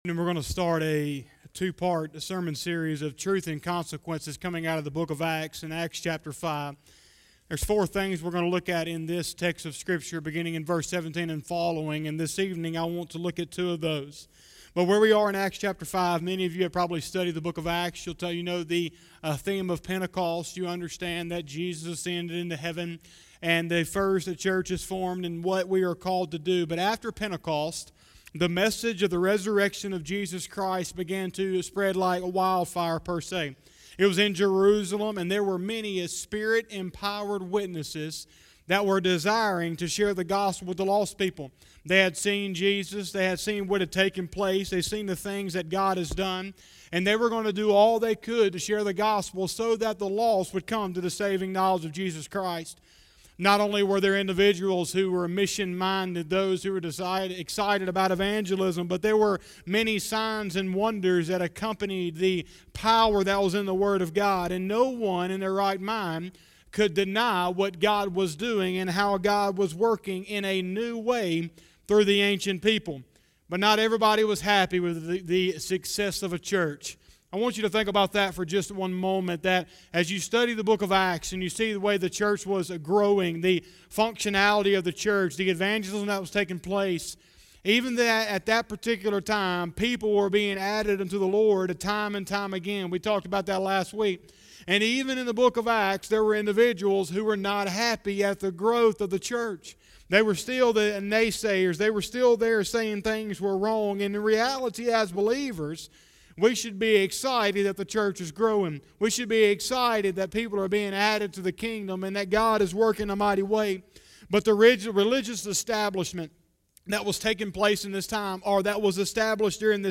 07/05/2020 – Sunday Evening Service